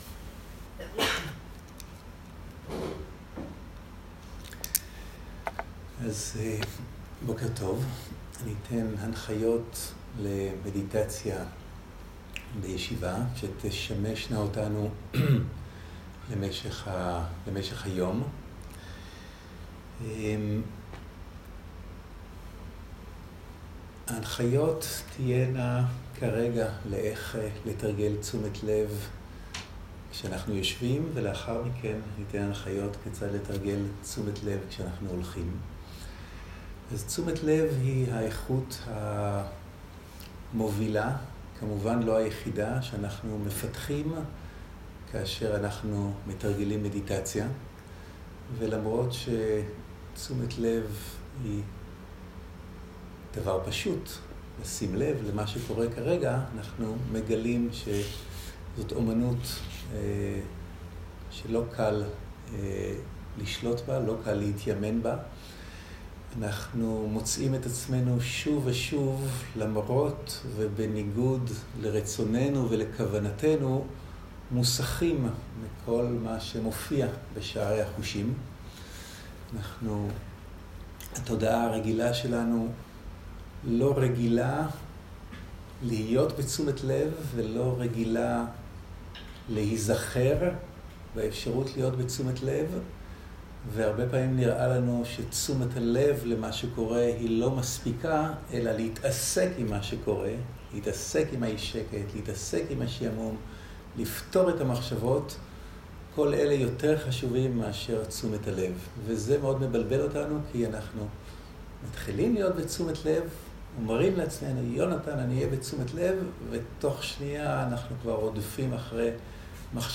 Dharma type: Guided meditation
Quality: high quality